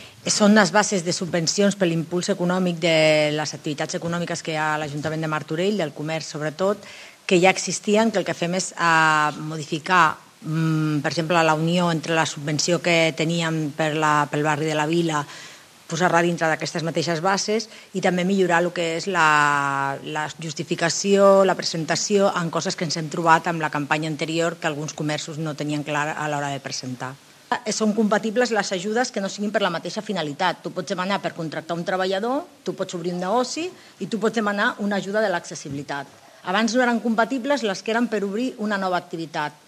Rosa Cadenas, regidora de Promoció Econòmica de l'Ajuntament de Martorell